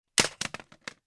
جلوه های صوتی
دانلود صدای تلویزیون 5 از ساعد نیوز با لینک مستقیم و کیفیت بالا